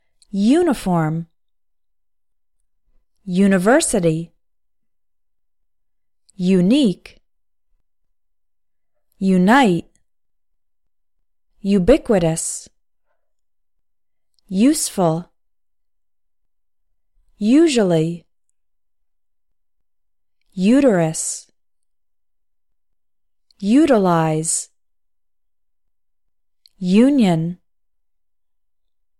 English words starting with U – “you” sound